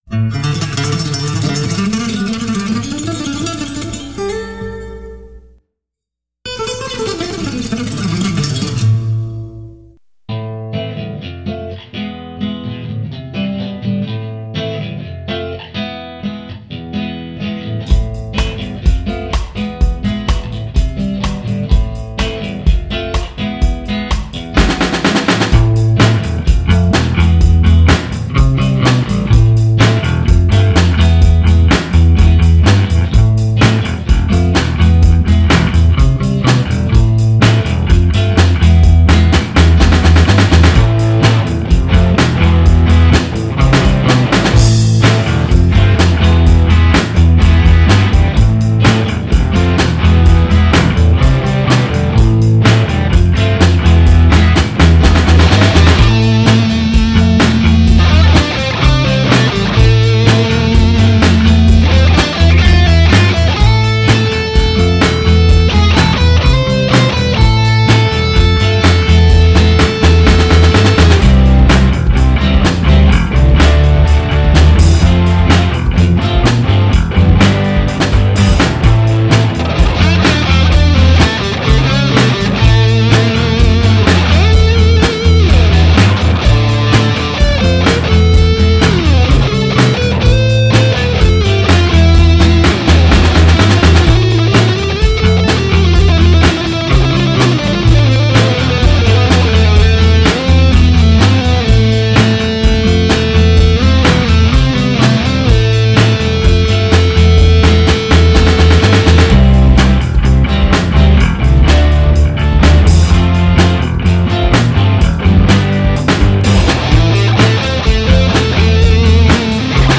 Sinfonia: Flamenco crossed with Carlos Santana at high speed.